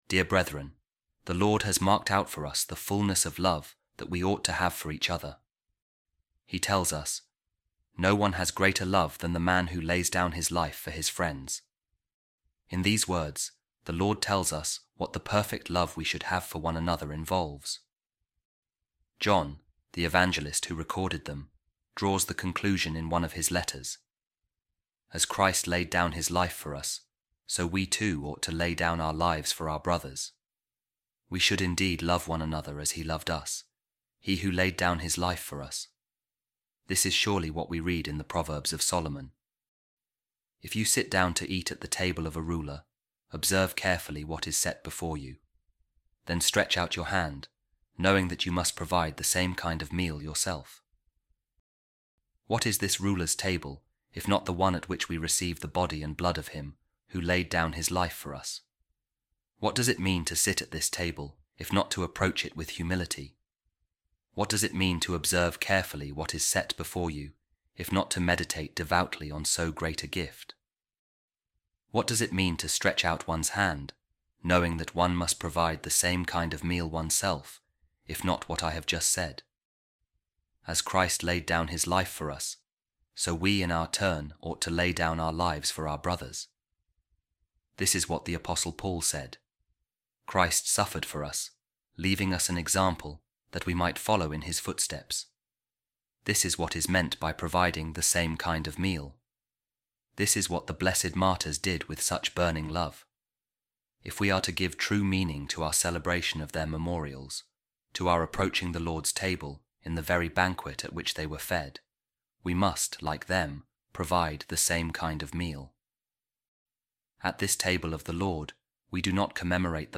A Reading From The Homilies Of Saint Augustine On Saint John’s Gospel